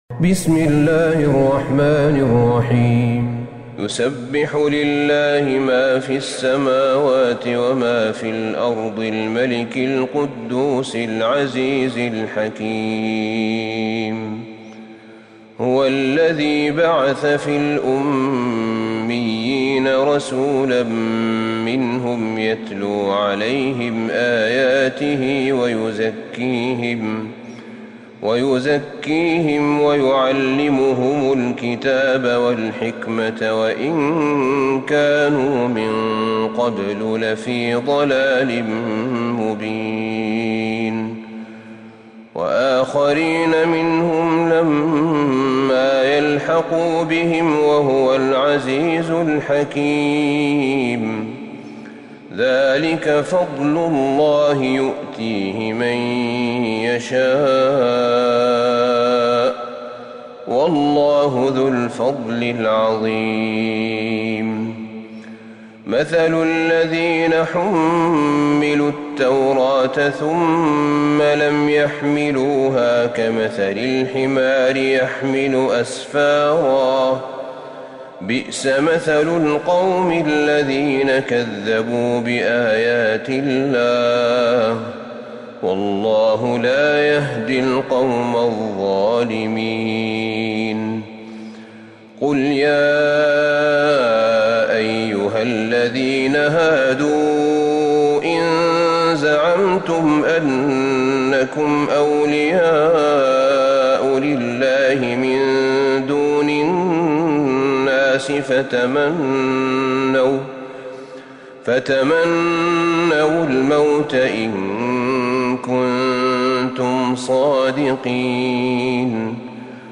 سورة الجمعة Surat Al-Jumu'ah > مصحف الشيخ أحمد بن طالب بن حميد من الحرم النبوي > المصحف - تلاوات الحرمين